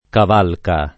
[ kav # lka ]